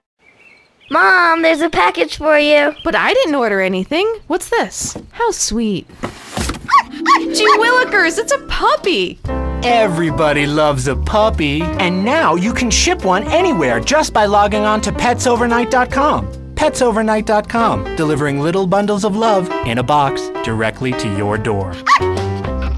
[[Category:Audio ads]]